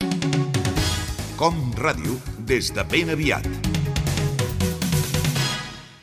Indicatiu de l'informatiu matinal